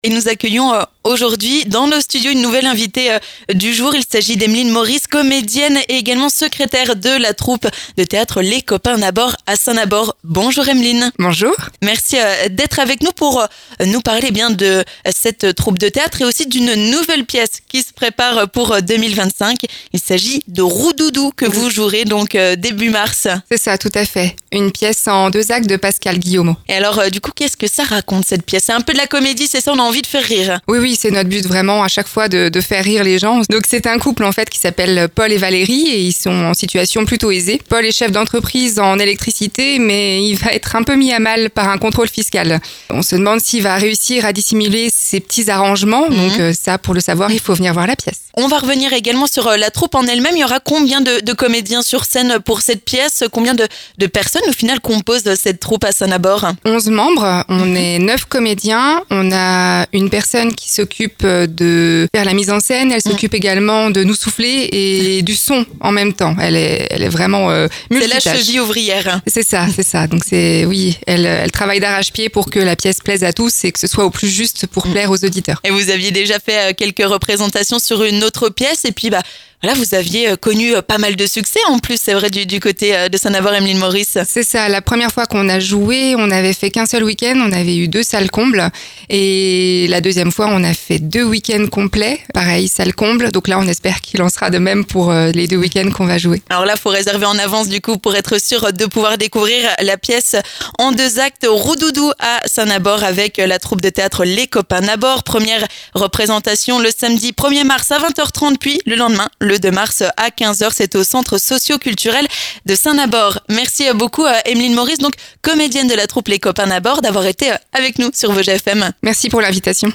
%%La rédaction de Vosges FM vous propose l'ensemble de ces reportages dans les Vosges%%